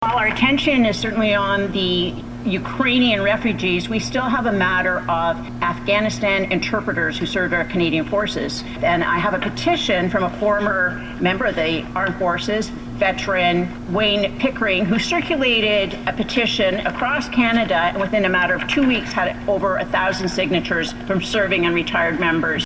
She presented the document Thursday in the House of Commons.